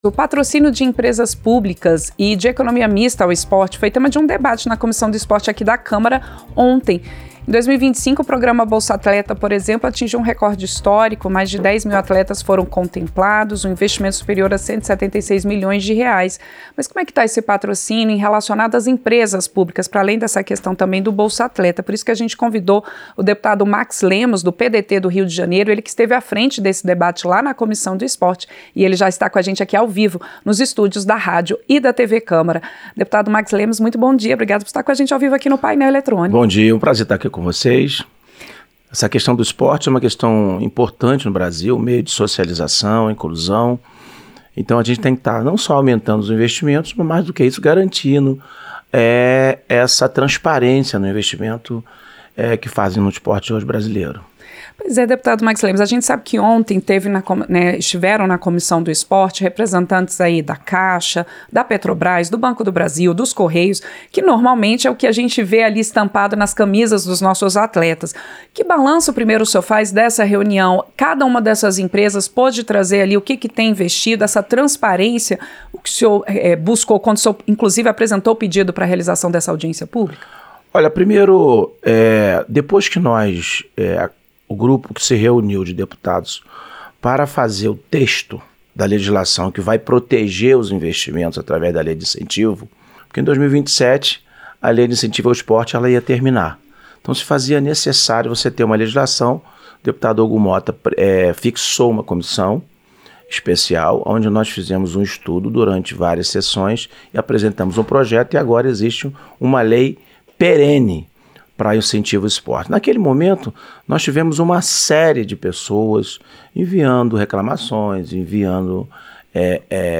Entrevista: Dep. Max Lemos (PDT-RJ)